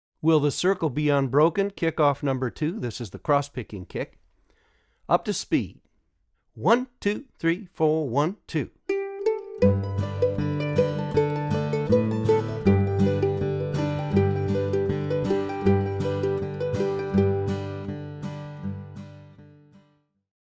DIGITAL SHEET MUSIC - MANDOLIN SOLO
Traditional Mandolin Solo
Online Audio (both slow and regular speed)